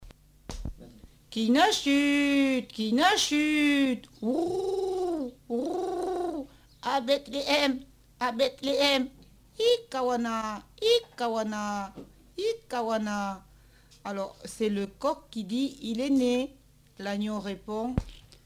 Mimologisme du coq